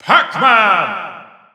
The announcer saying Pac-Man's name in English and Japanese releases of Super Smash Bros. 4 and Super Smash Bros. Ultimate.
Pac-Man_English_Announcer_SSB4-SSBU.wav